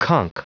Prononciation du mot conch en anglais (fichier audio)
Prononciation du mot : conch